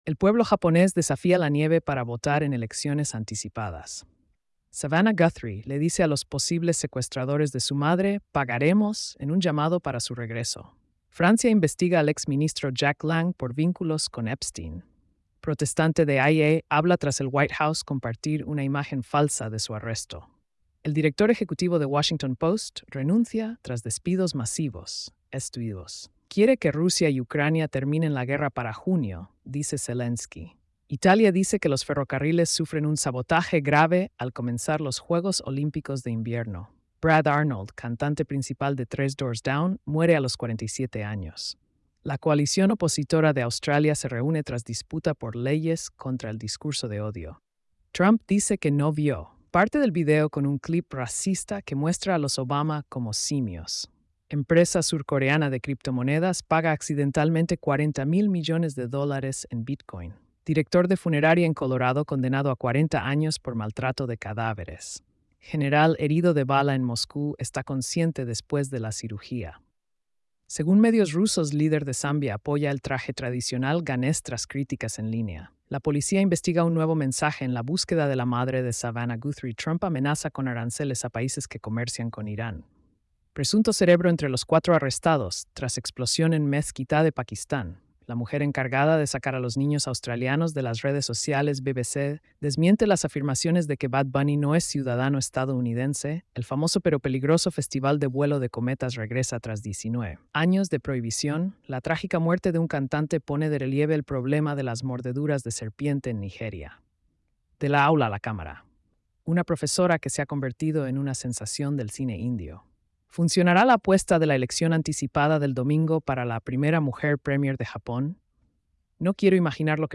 🎧 Resumen de noticias diarias. |